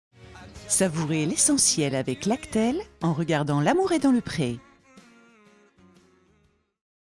Bandes-son
voix off bilboard